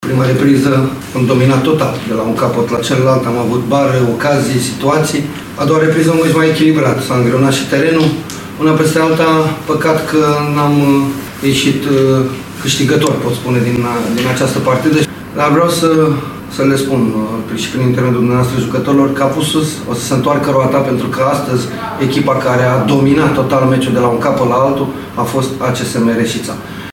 Antrenorul principal al reșițenilor, Flavius Stoican, regretă că dominarea din teren, mai ales din prima parte, nu s-a reflectat și în rezultatul final al întâlnirii: